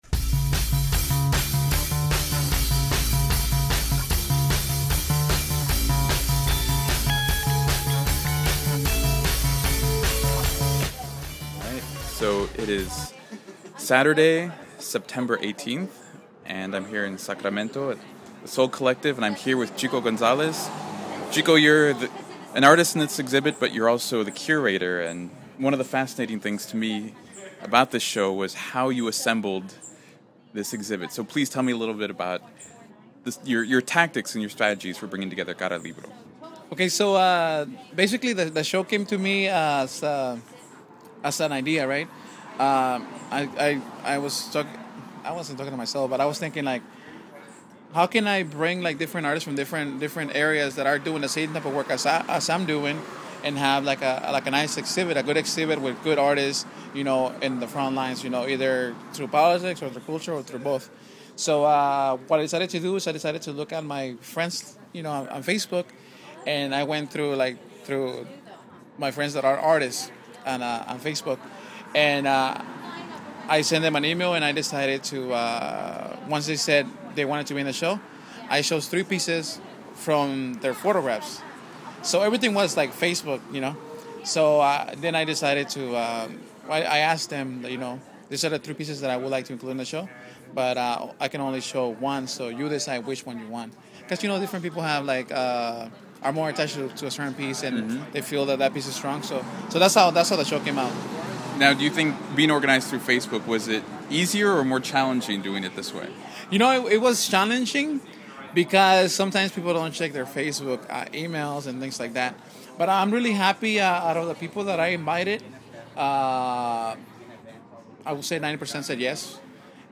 It was my first time at Sol Collective and I was impressed by the space.